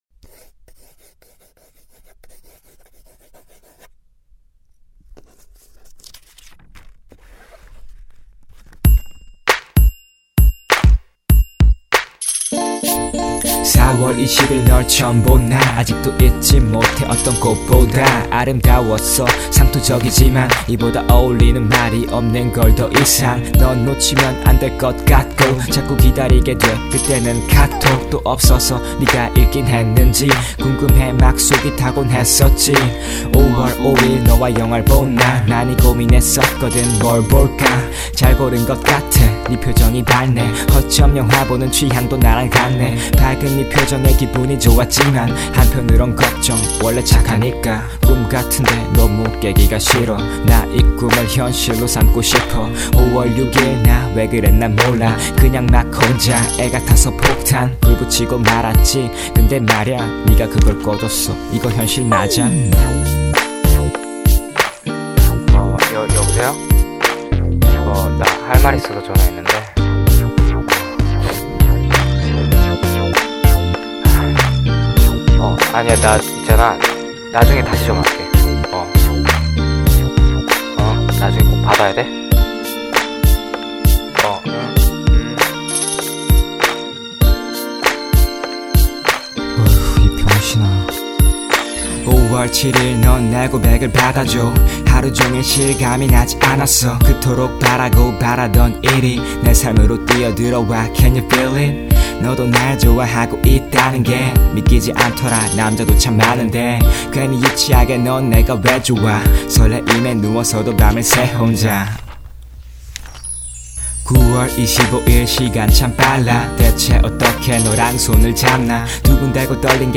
(Verse1에서 박자한번 삐끗하네요ㅠㅠ)
2. 그 전곡은 못들어봤지만 플로우가 많이 단조롭네요
근데 아직도 발성이 약간 덜 된 느낌이랄까 그런게 남아있는 것 같아
플로우도 너무 단조롭고, 뭔가 새롭게 할려고 하는 것 같은데 안되서